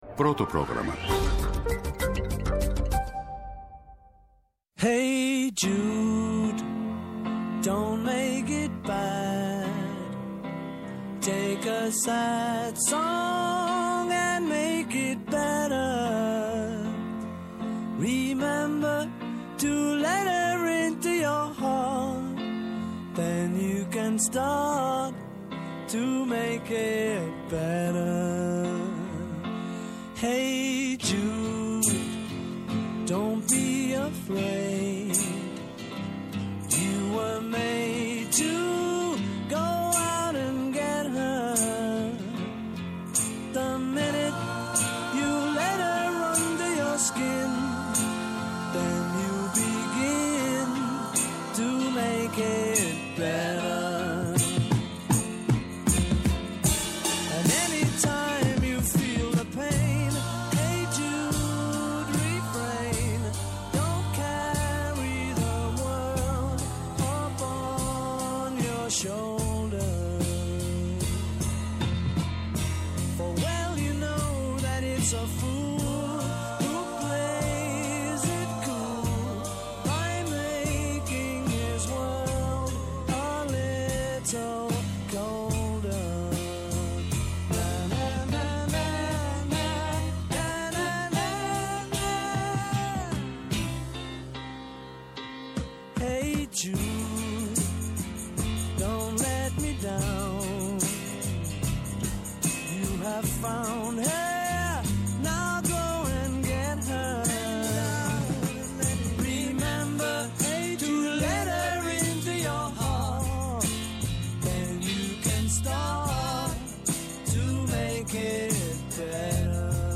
Καλεσμένος στο στούντιο της εκπομπής